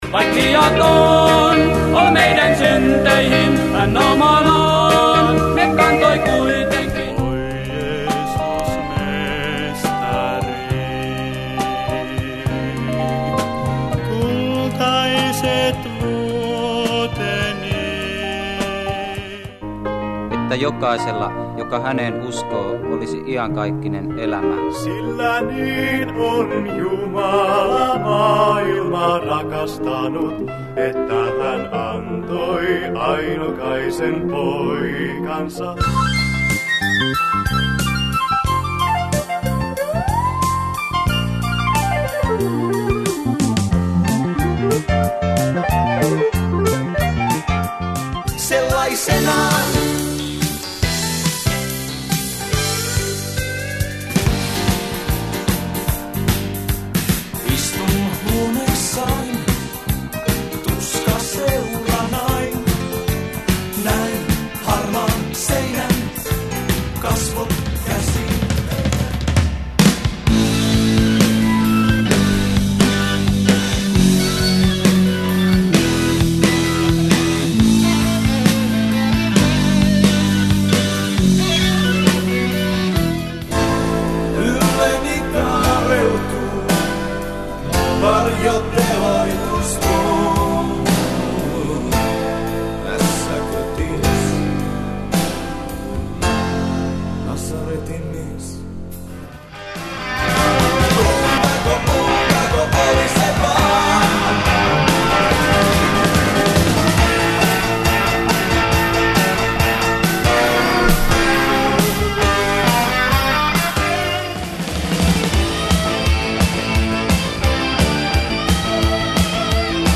Rautalankaa, progea, rokkia, poppia…